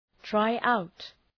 Προφορά
{‘traı,aʋt}
try-out.mp3